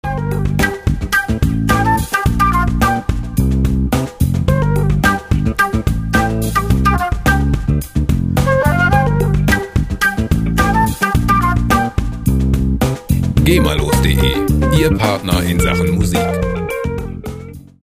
Funk Loops GEMA-frei
Musikstil: Fusion
Tempo: 108 bpm